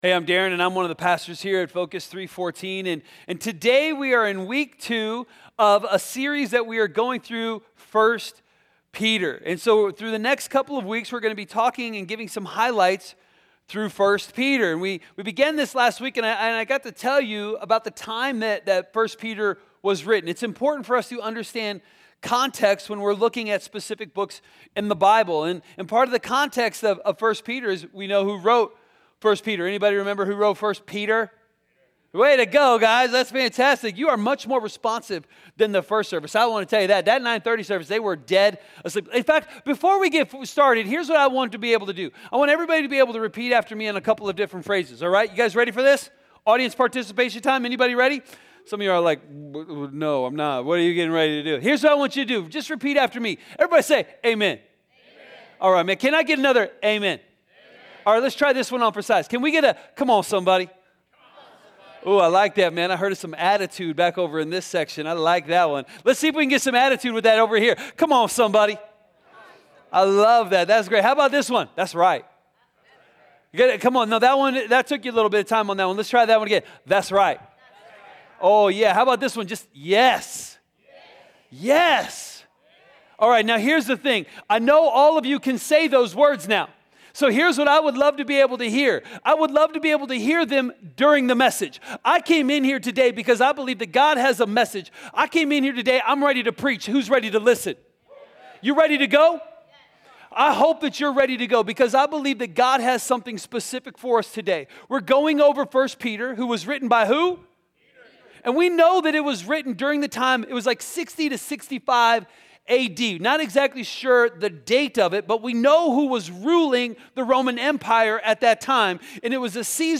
A message from the series "Different."